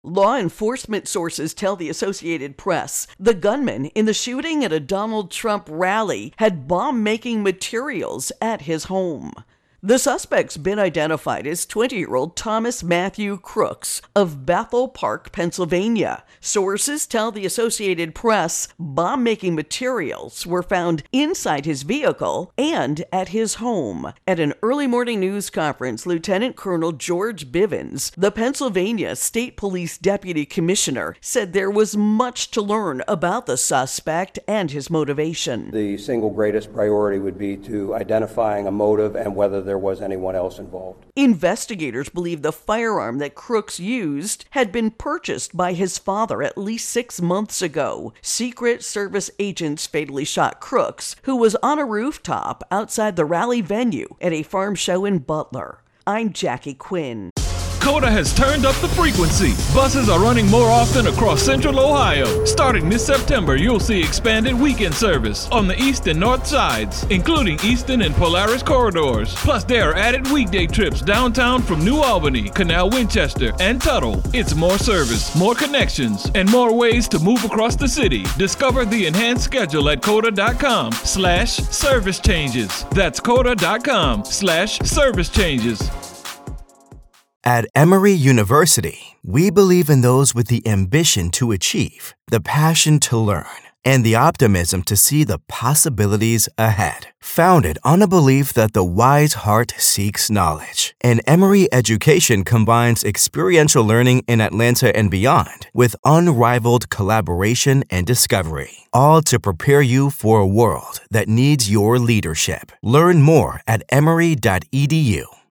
AP correspondent
reports